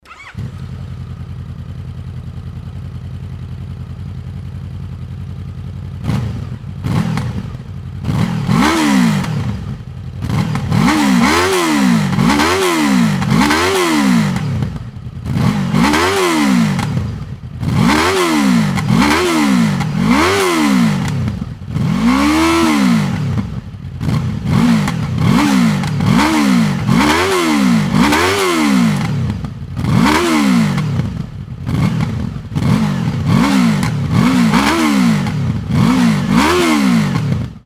それでもCB1300と比べると静かに思えます。
V型4気筒エンジンの独特なサウンドなんですが
空吹かしの排気音を収録したのでどうぞ・・
VFR800Fの排気音
rc79_vfr800f-normal.mp3